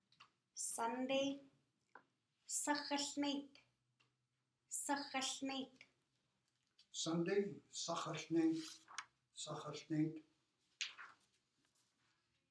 These words and greetings in Hul’q’umi’num’ were recorded by a district elder and are offered as a way to learn and practice the language.